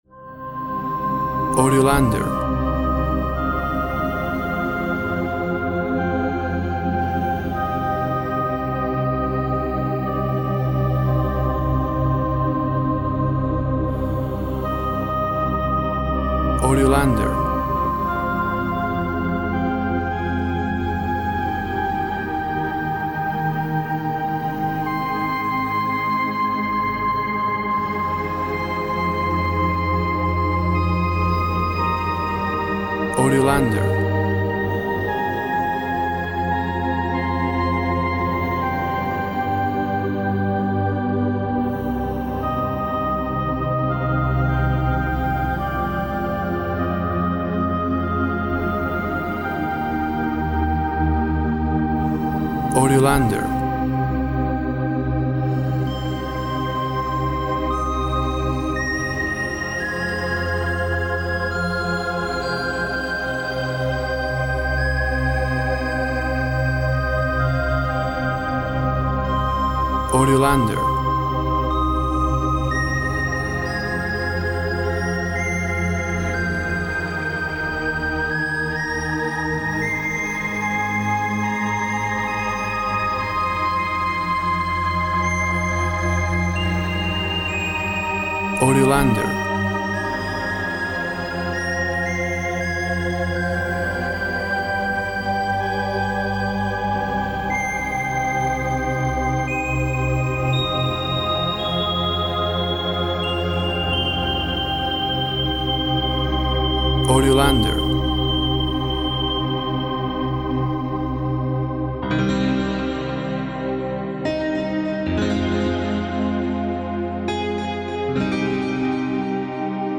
WAV Sample Rate 24-Bit Stereo, 44.1 kHz
Tempo (BPM) 60